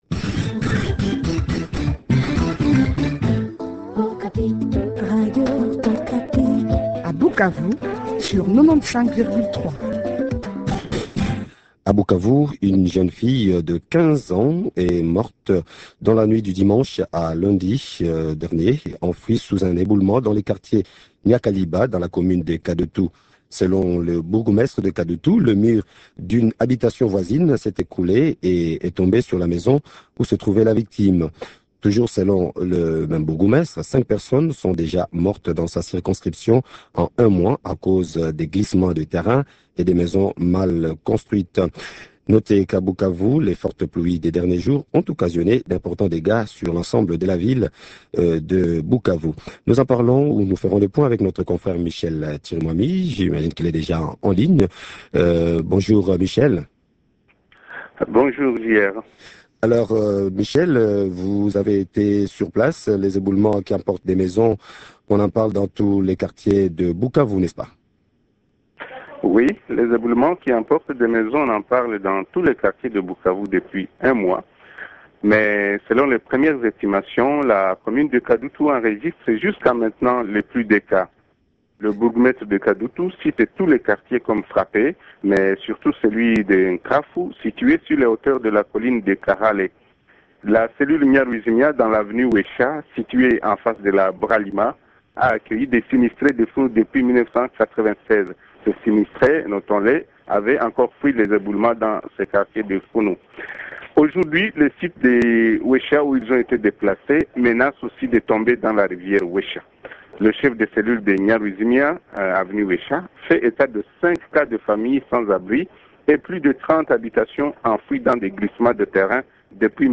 L?essentiel de l?entretien dans cet élément.